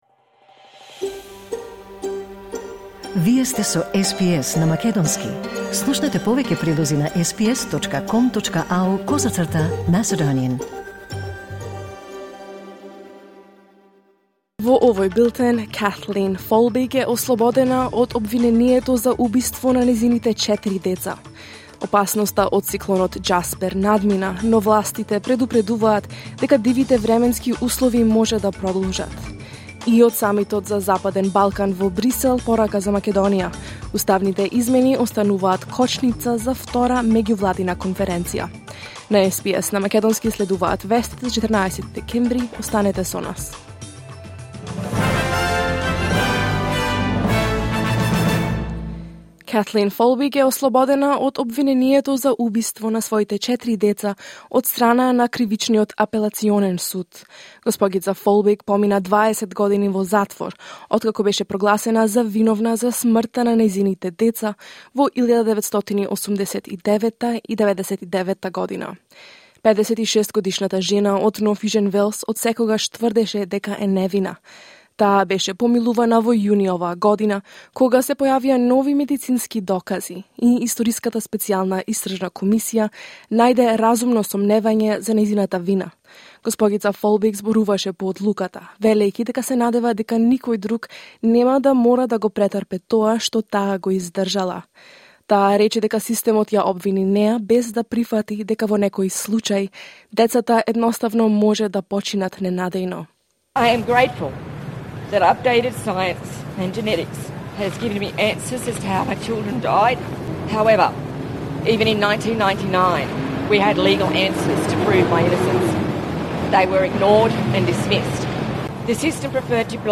SBS News in Macedonian 14 December 2023